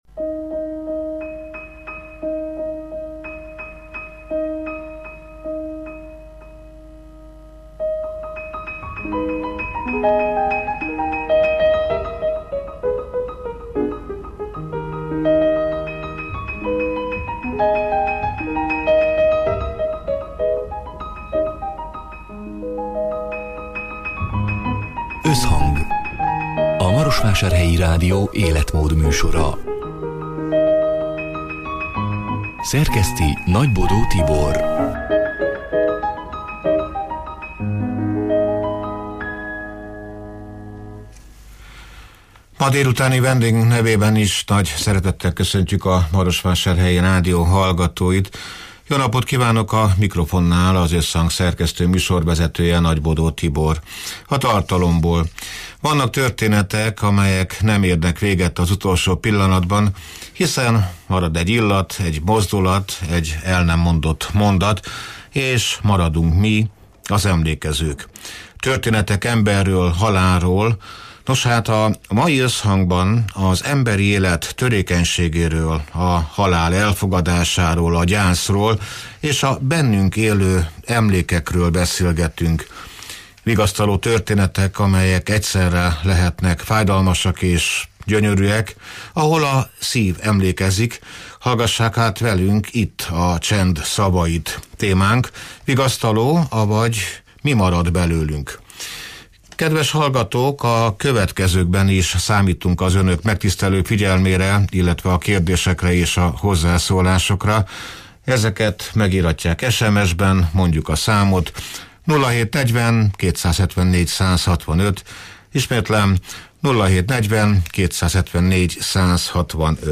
(elhangzott: 2025. május 28-án, szerdán délután hat órától élőben)